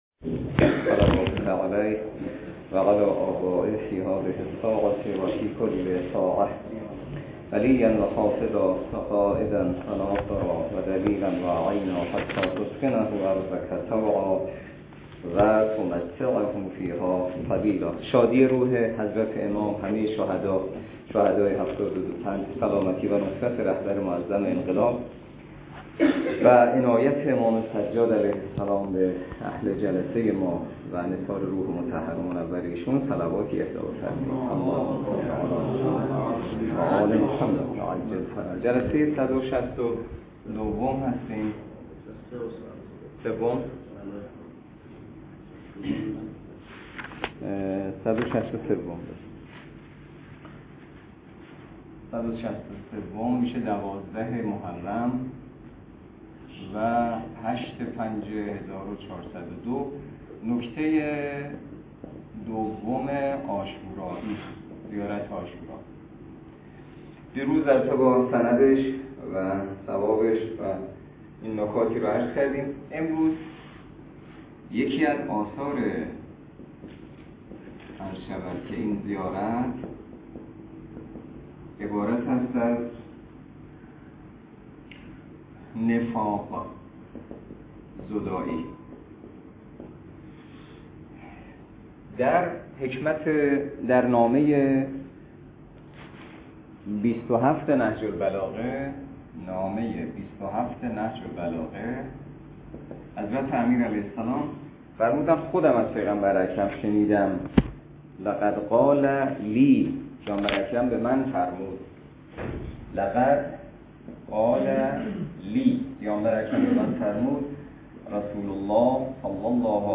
درس فقه الاجاره نماینده مقام معظم رهبری در منطقه و امام جمعه کاشان - جلسه صد و شصت و سه .